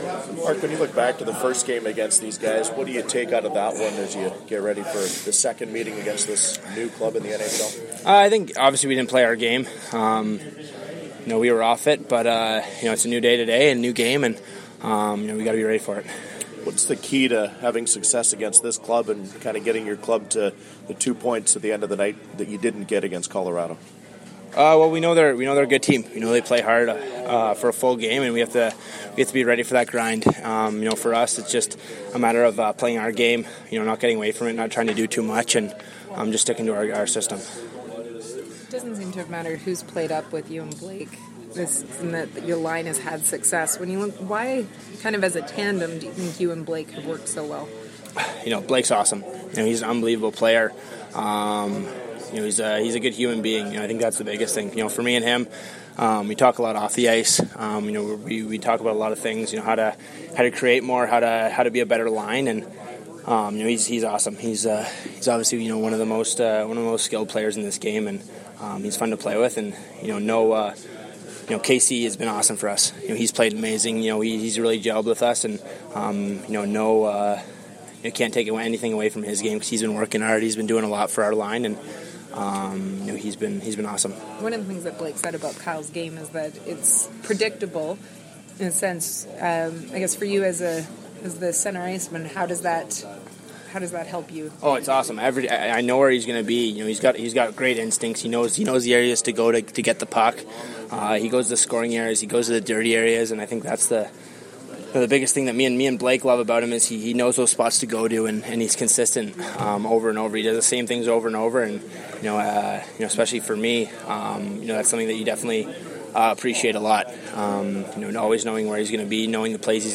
December-1-2017-Mark-Scheifele-pre-game.mp3